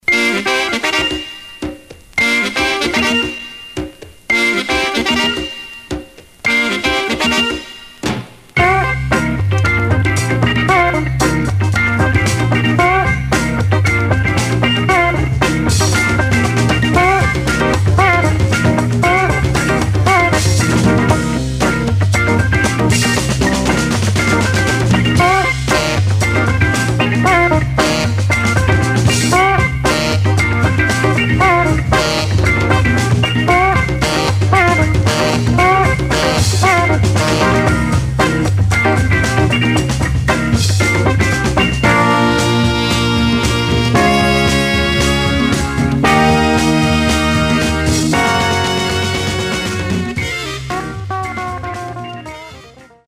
Stereo/mono Mono
Funk